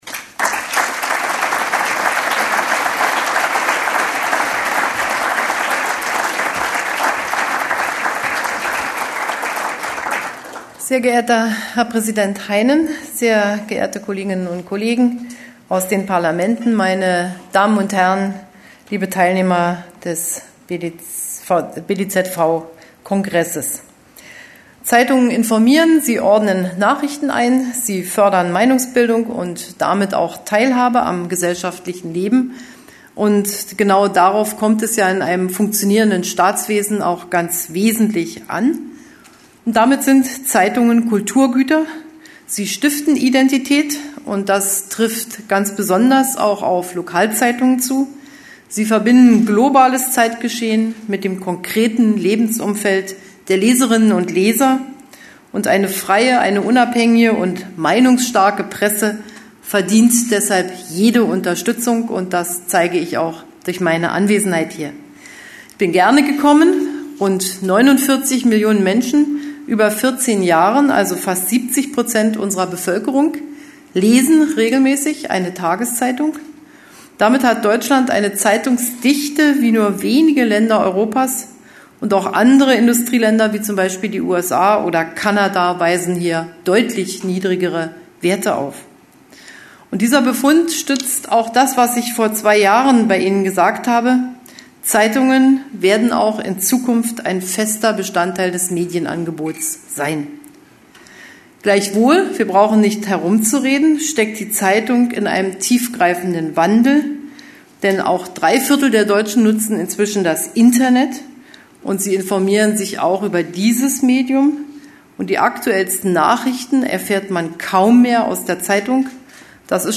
Wer: Dr. Angela Merkel, Bundeskanzlerin der Bundesrepublik Deutschland Was: Rede zum Zeitungskongress des BDZV Wo: Berlin, Maritim proArte Hotel Wann: 19.09.2011, ca. 15:15 Uhr